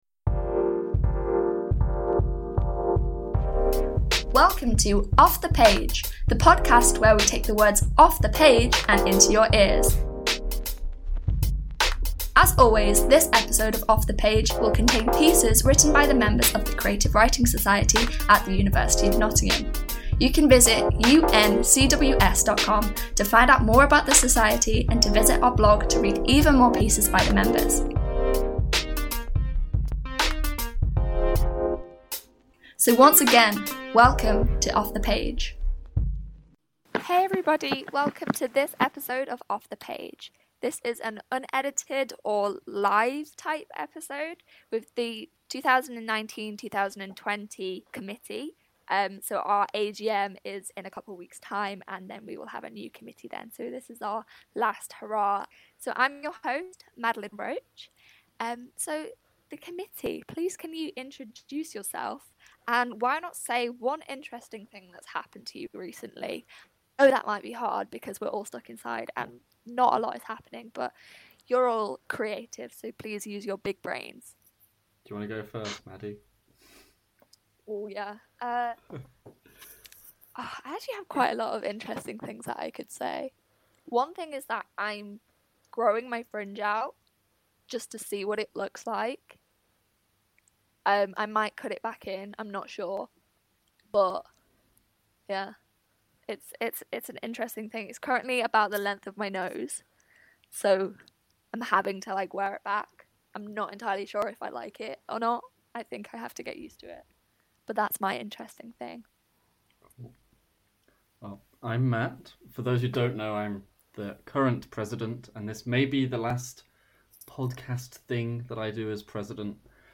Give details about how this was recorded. This episode is an unedited/“live” chat between the 2019/2020 committee who joined an audio call to discuss this past year of Creative Writing.